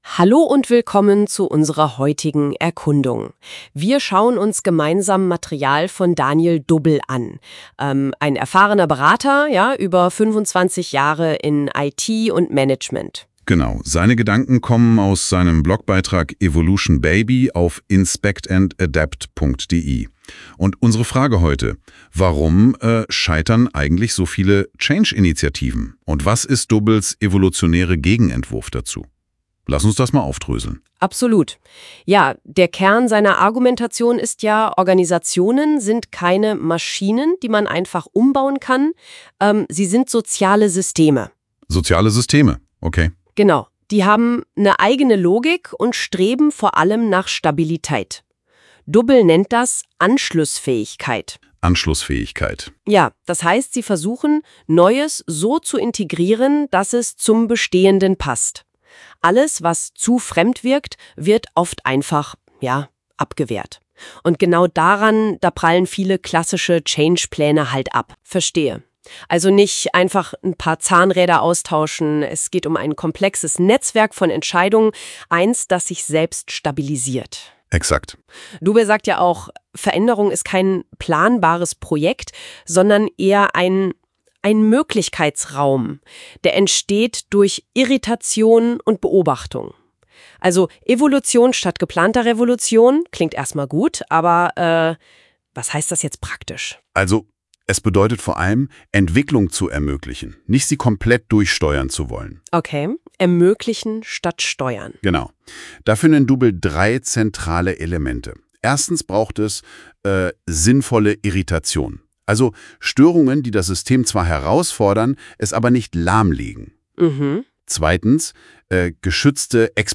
Hier bekommst du einen durch NotebookLM generierten KI-Podcast Dialog zu diesem Artikel.